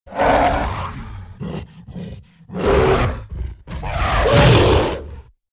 animal
Gorilla Roars And Breaths 2